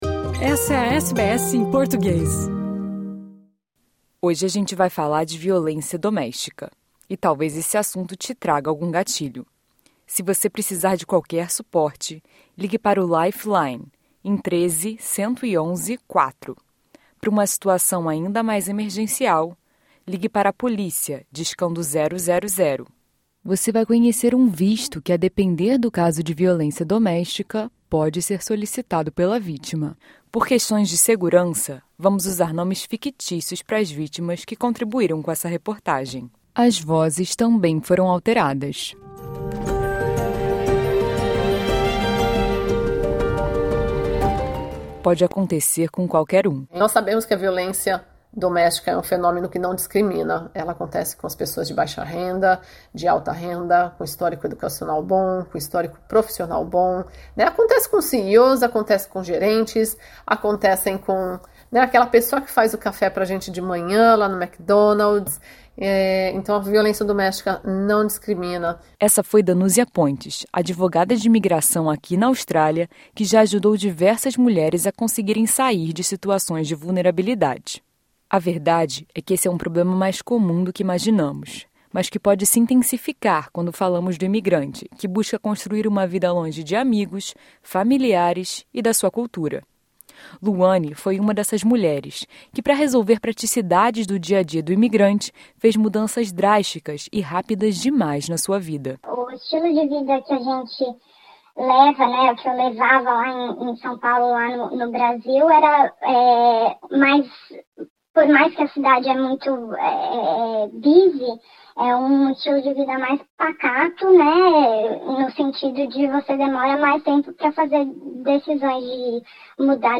O problema é mais comum do que parece no país e muitas vezes afeta pessoas que jamais imaginaríamos. Conversamos com algumas dessas vítimas e descobrimos como saíram dessa situação - seus nomes aqui são fictícios e suas vozes foram alteradas por segurança.